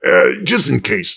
woodboxdestroyed03.ogg